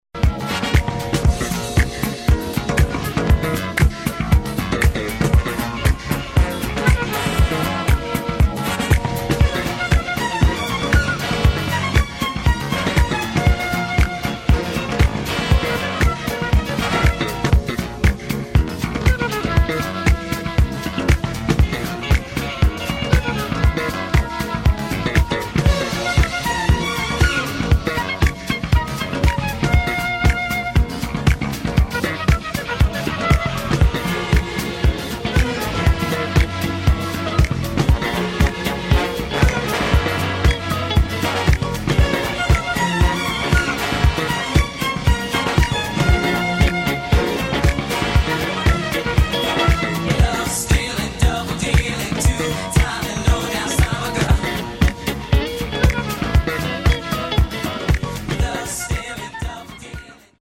[ DISCO | FUNK | SOUL ]